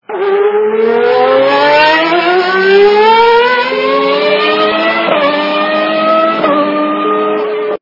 » Звуки » звуки для СМС » Cars traffic - Ferrari Formel 1 Motorsound
При прослушивании Cars traffic - Ferrari Formel 1 Motorsound качество понижено и присутствуют гудки.
Звук Cars traffic - Ferrari Formel 1 Motorsound